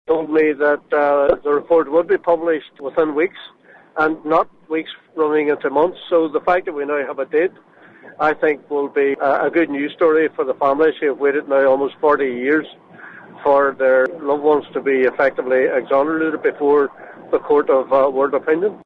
Sinn Fein’s Martin McGuinness says the British Prime Minister has kept his word to swiftly publish the document…………..